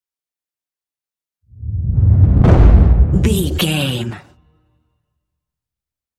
Dramatic deep whoosh to hit trailer
Sound Effects
Atonal
dark
intense
tension
woosh to hit